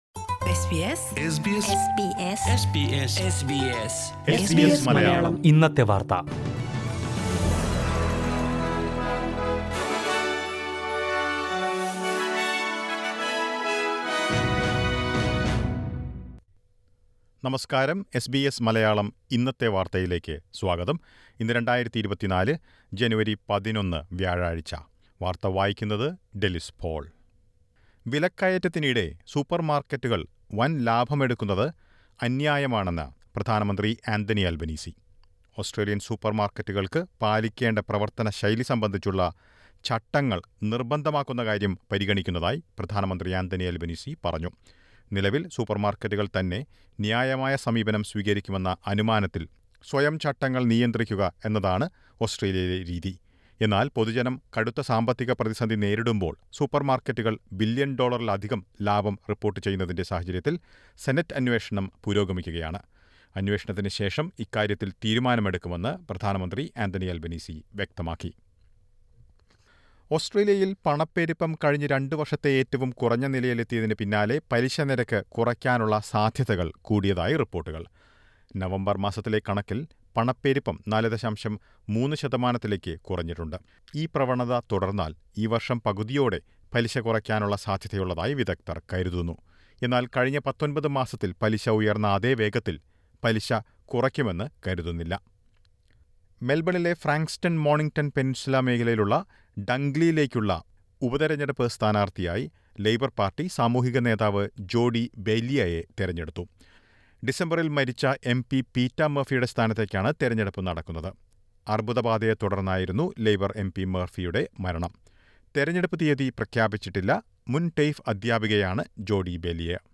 2024 ജനുവരി 11 ലെ ഓസ്‌ട്രേലിയയിലെ ഏറ്റവും പ്രധാന വാര്‍ത്തകള്‍ കേള്‍ക്കാം...